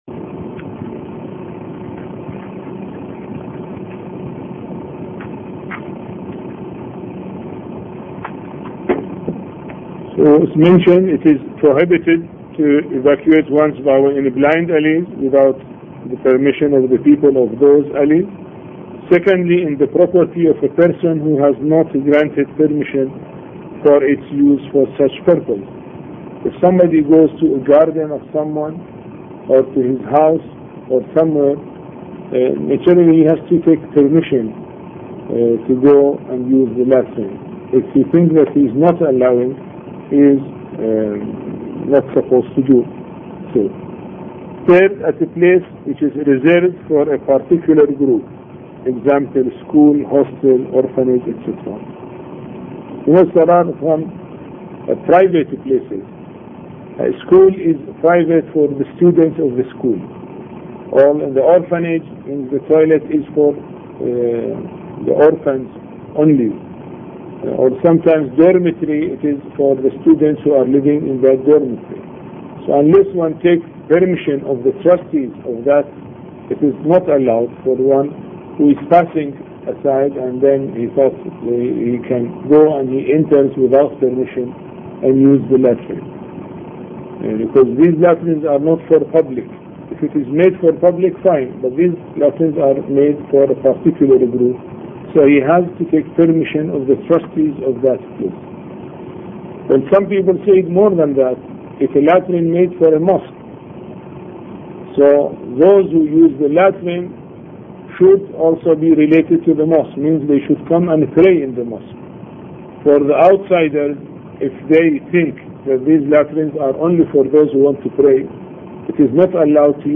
A Course on Fiqh Lecture 2